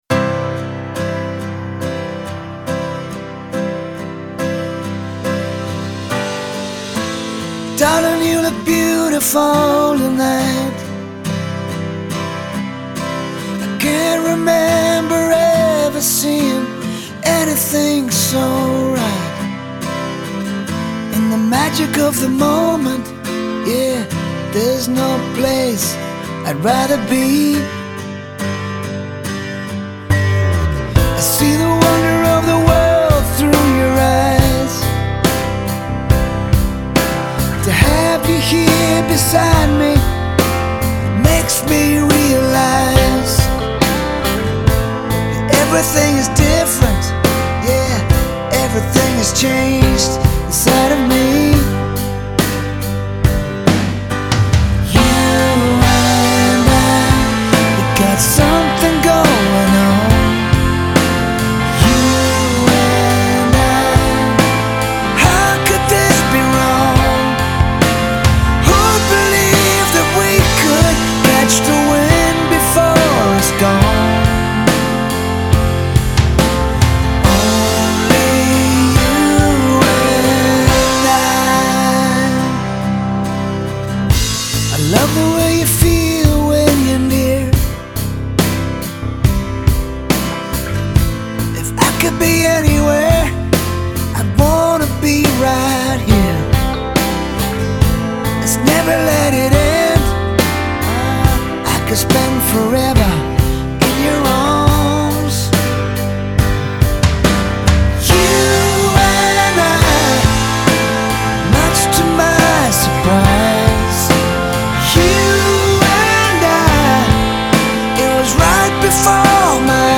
Genre : Comédies musicales